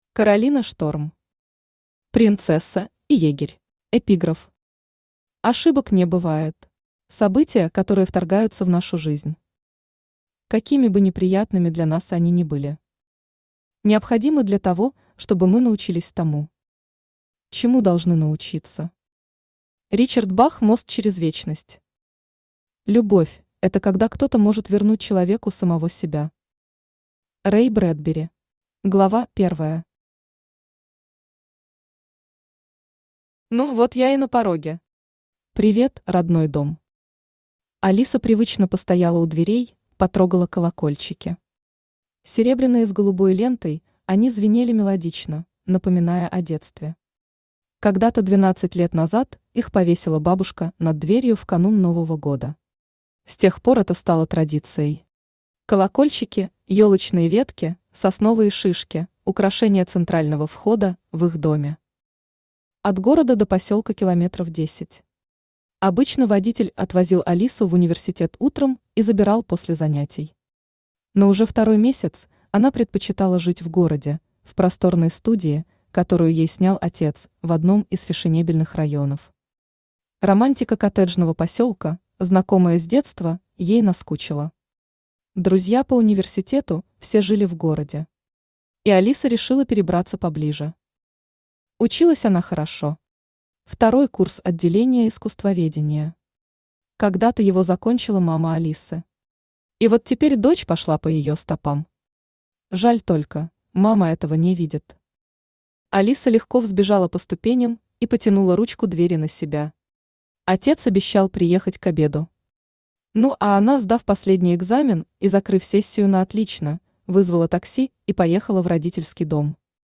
Аудиокнига Принцесса и егерь | Библиотека аудиокниг
Aудиокнига Принцесса и егерь Автор Каролина Шторм Читает аудиокнигу Авточтец ЛитРес.